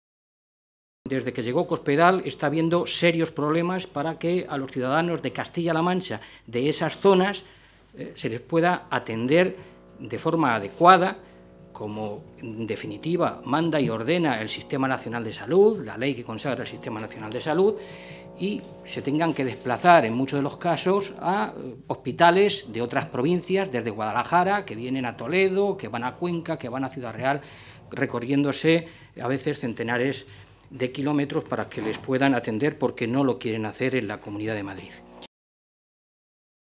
Valentín se pronunciaba de esta manera esta mañana, en Toledo, en una comparecencia ante los medios de comunicación, en la que, con los datos oficiales a fecha 28 de Febrero pasado, constataba “el desmantelamiento de la Ley de la Dependencia en la región desde que gobierna Cospedal”.